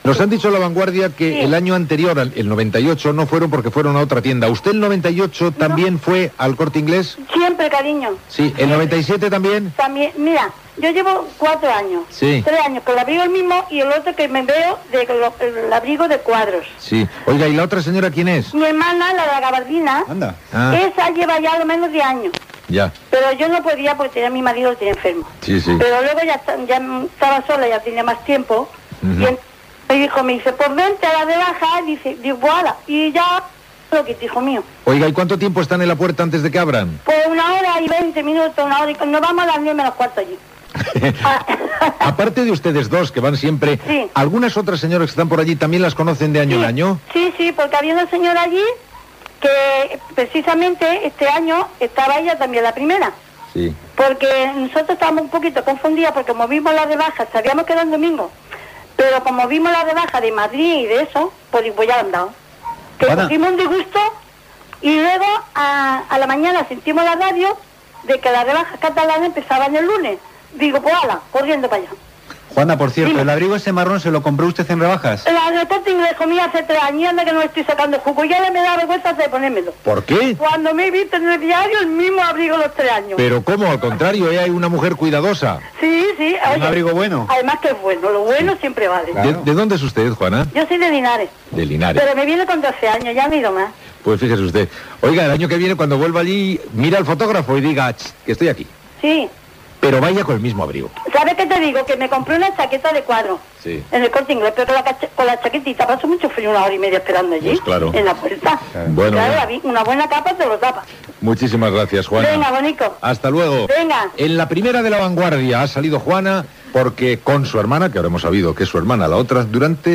Entrevista a una senyora sobre el primer dia de les rebaixes de gener del magatzem El Corte Inglés
Info-entreteniment